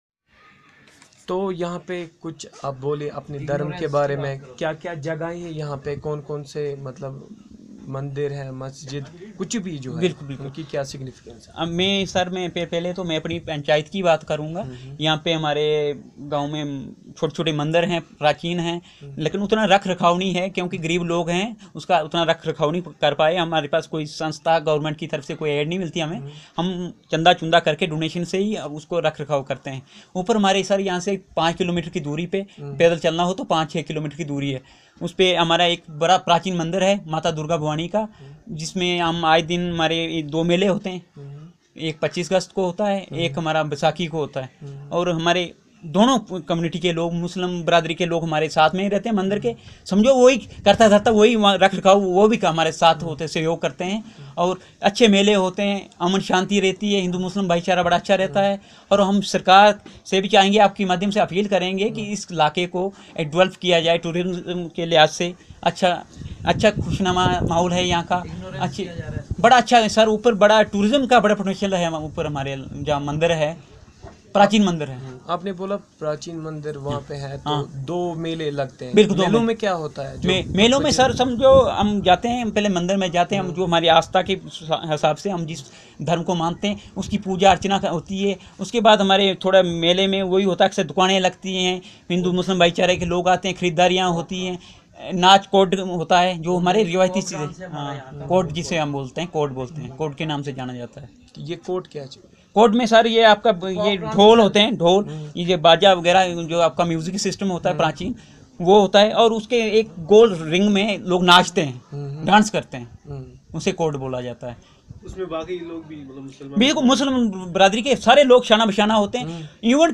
Conversation about the places of worship and religious harmony